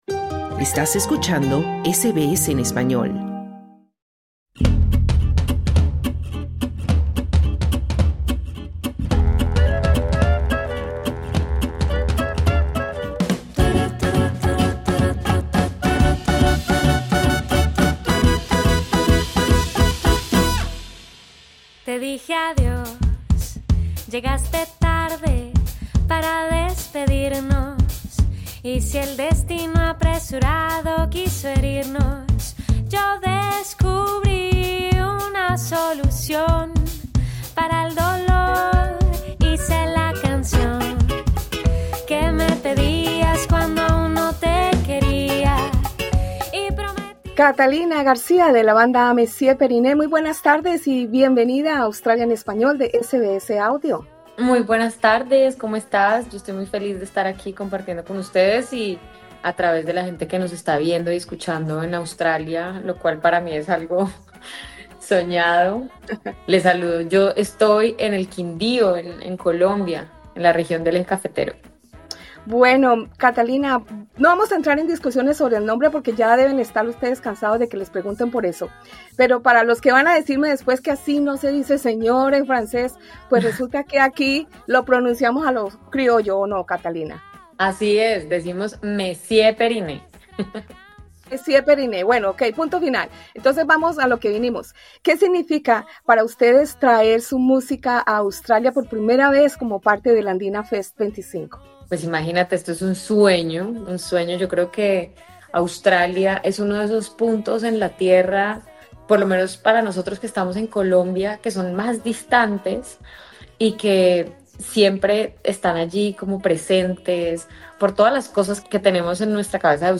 Credit: Supplied by Monsieur Periné En entrevista con SBS Australia en Español, la cantante Catalina García comparte su emoción por visitar tierras australianas y departir con sus paisanos, a quienes aspira con su música traerles un pedacito de su patria.